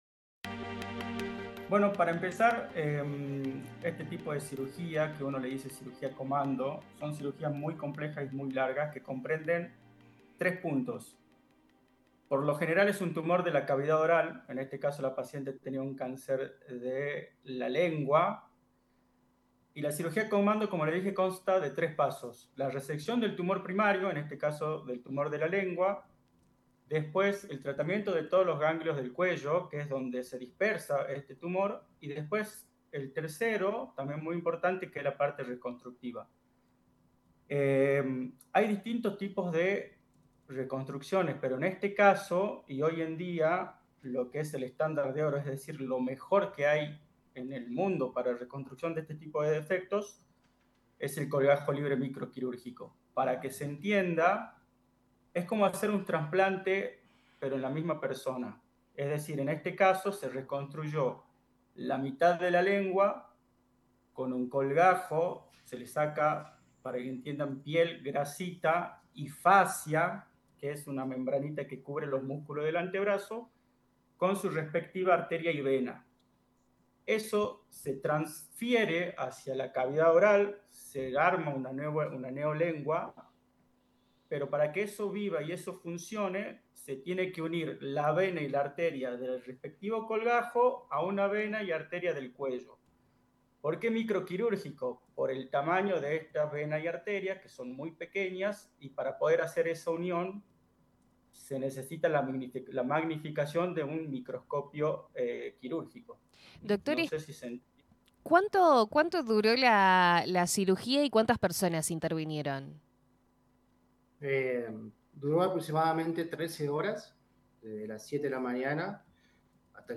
Este episodio del podcast reúne el testimonio de los médicos cirujanos que participaron de la operación, quienes relatan cómo se organizó el equipo, los desafíos que implicó la intervención y la trascendencia que este avance tiene para la salud pública en Jujuy.